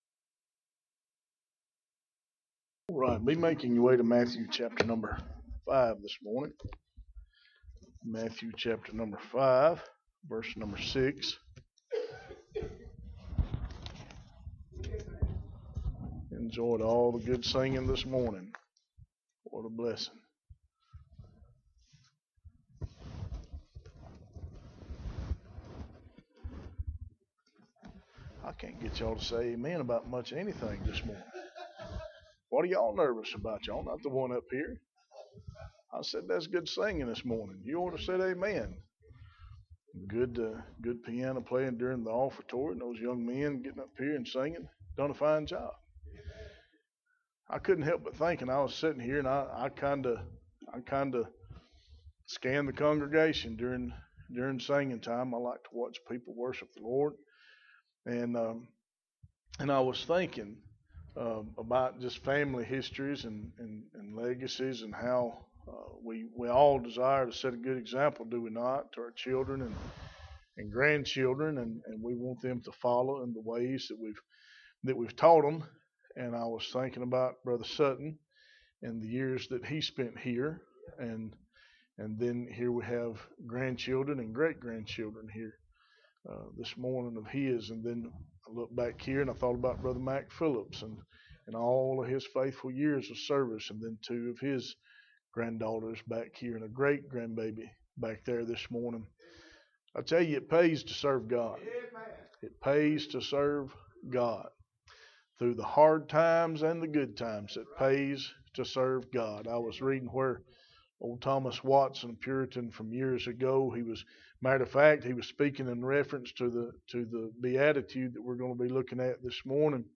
Sermon on the Mount Pt.5 Beatitude 4 – Unity Baptist Church